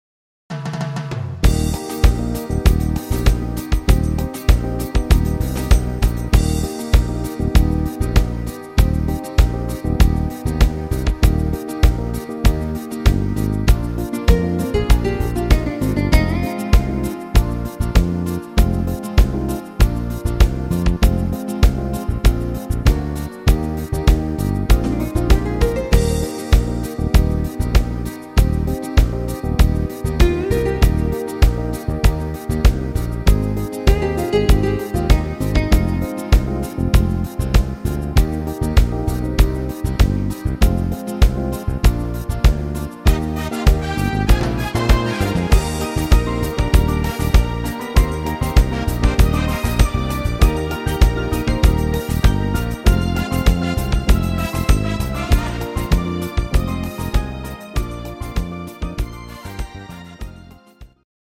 Rhythmus  Samba